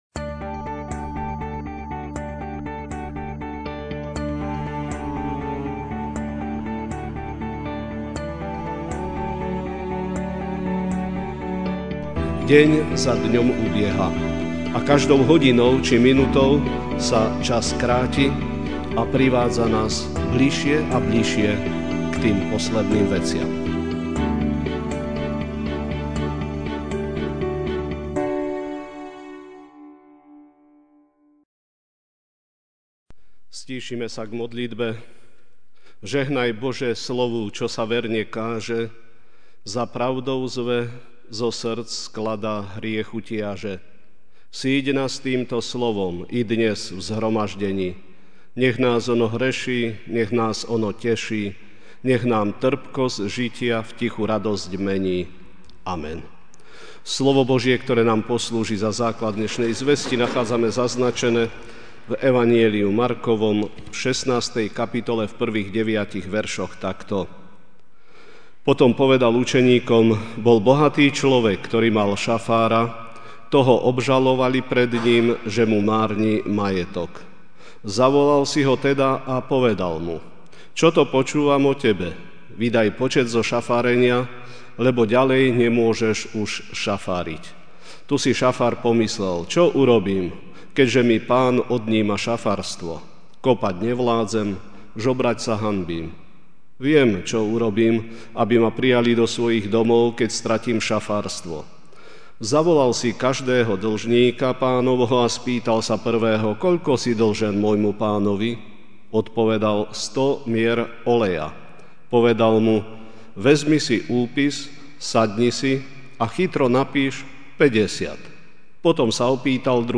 Večerná kázeň: Čo robiť aby sme boli spasení?